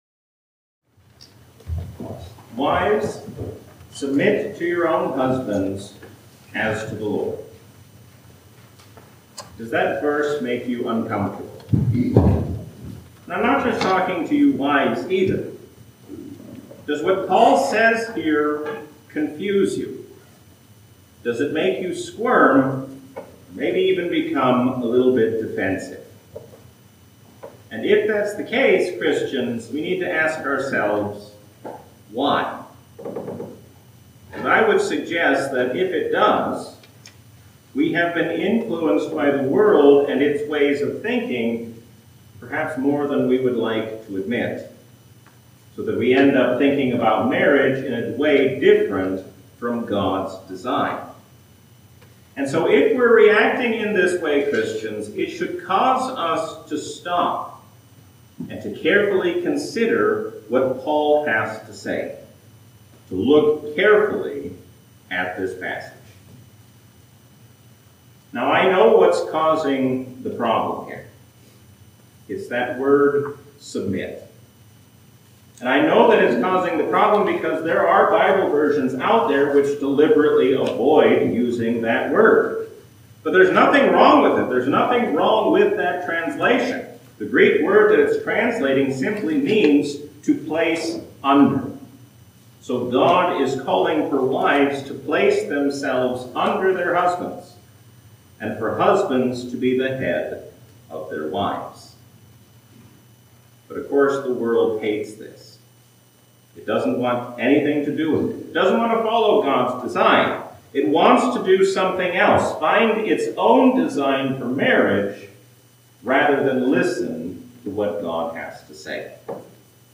A sermon from the season "Epiphany 2025." Stephen shows us what it means to be like Jesus even in a difficult hour.